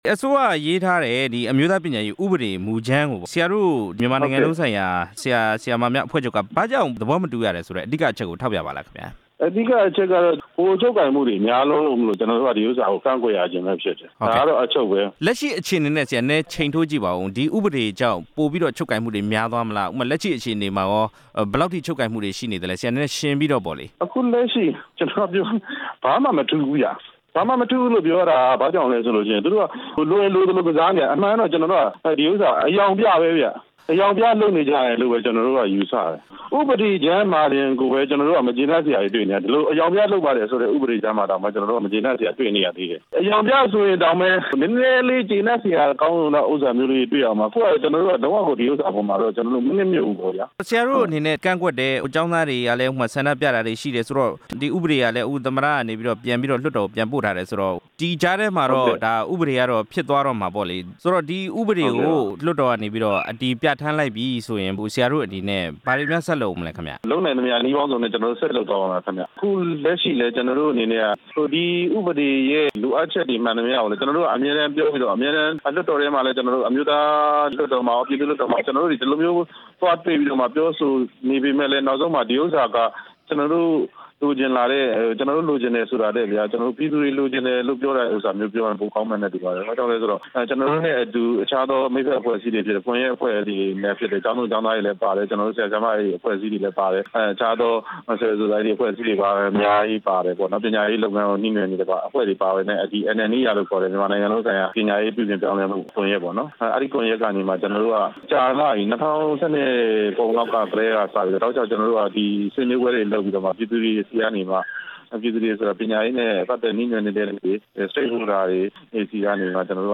မြန်မာနိုင်ငံလုံးဆိုင်ရာ ဆရာ၊ ဆရာမများအဖွဲ့ချုပ် နဲ့ မေးမြန်းချက်